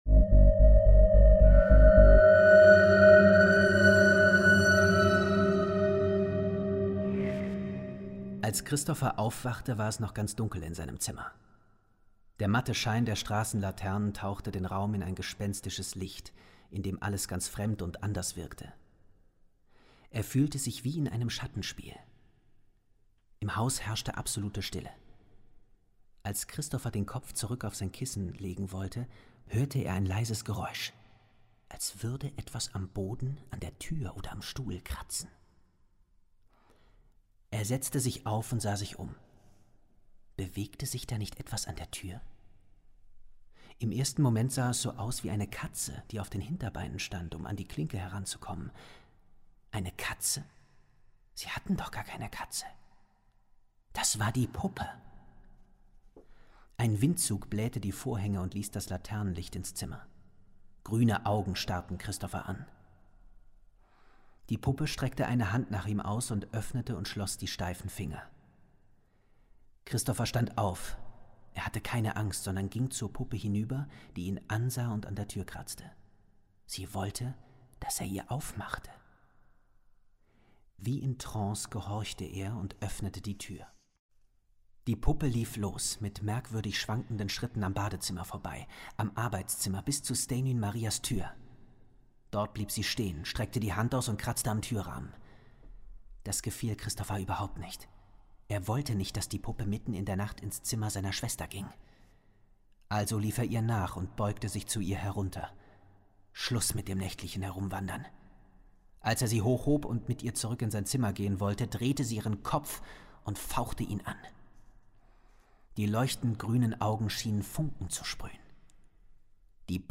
Hörbuch, 2 CDs, ca. 150 Minuten
"Außergewöhnliche Geschichte mit besonders dichter Atmosphäre und hochklassiger Gruselstimmung.
Qualitativ hochwertige Produktion und ein ausgezeichneter Sprecher bescheren puren gruseligen Hörgenuß, der das hohe Level der voran gegangenen Teile mühelos hält.